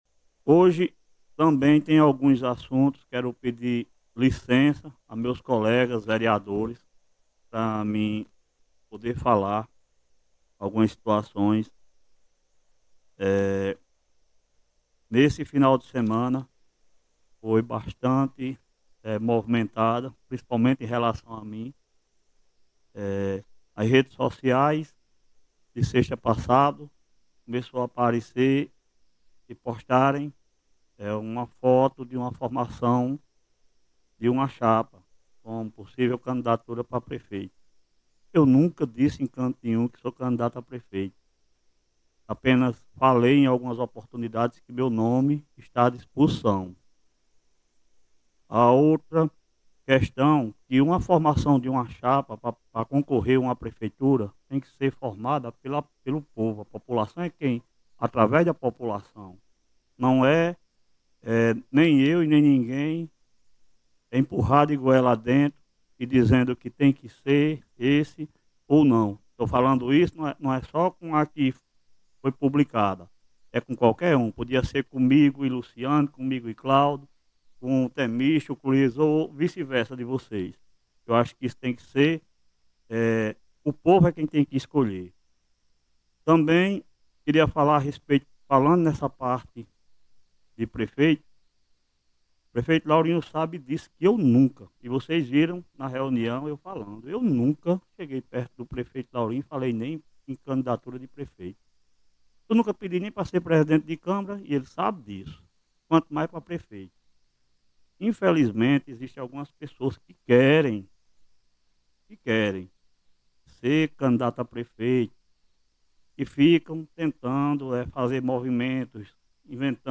O presidente da Câmara Municipal de Catolé do Rocha, vereador Cleverlando Barreto, utilizou a tribuna para rebater informações que circularam nas redes sociais no último fim de semana insinuando a formação de uma chapa com seu nome para disputar a Prefeitura.
Em pronunciamento firme, Cleverlando classificou a publicação feita sem fundamento e pediu a retirada imediata do conteúdo.